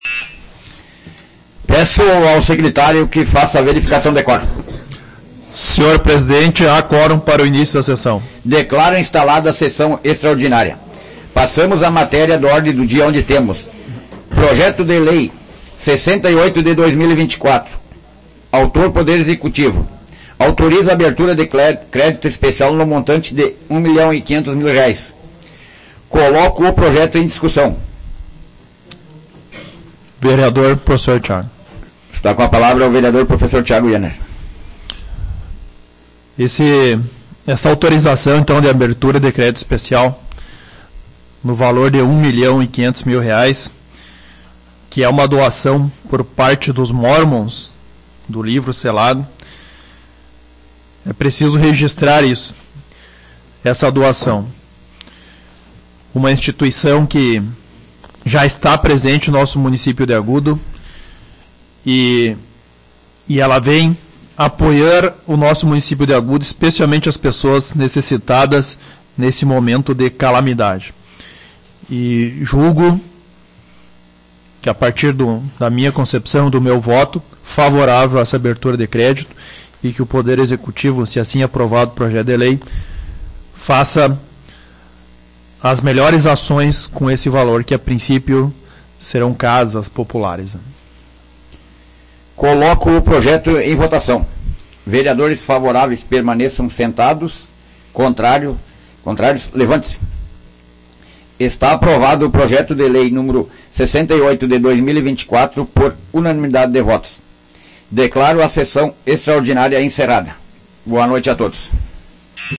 Áudio da 87ª Sessão Plenária Extraordinária da 16ª Legislatura, de 1º de julho de 2024